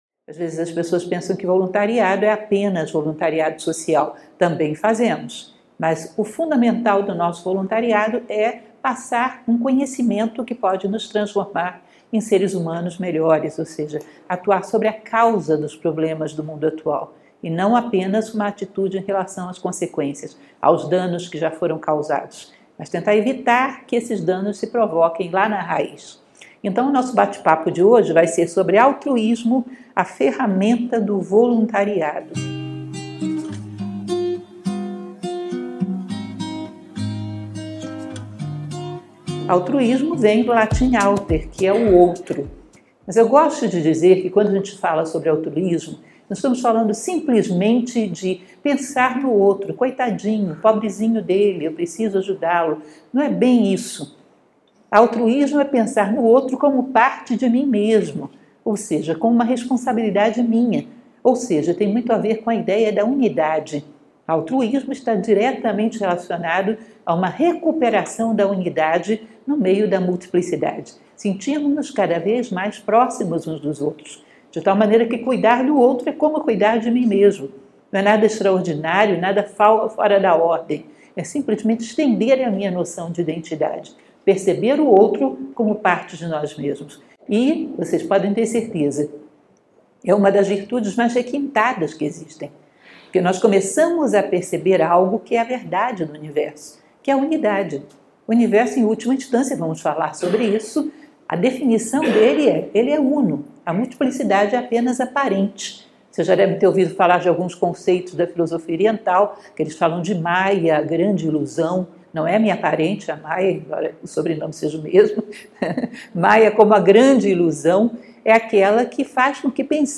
Palestras Filosóficas Nova Acrópole